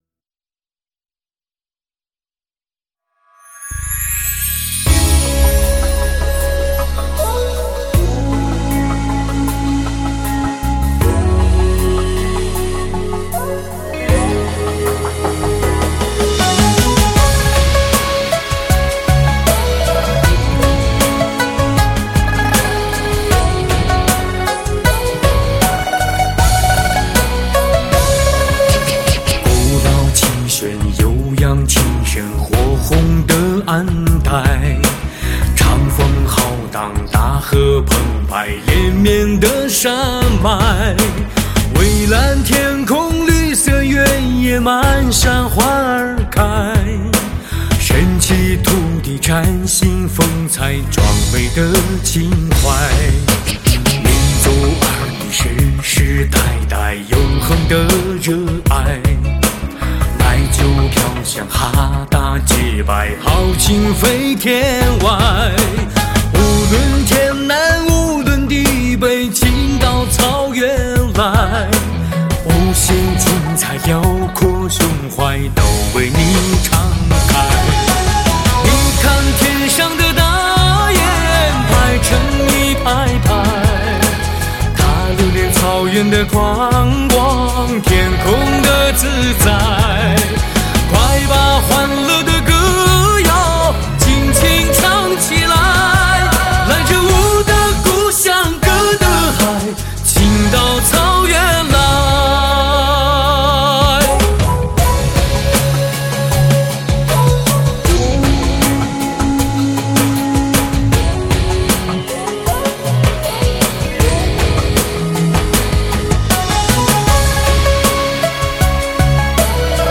有深情··有力度~~~赞！！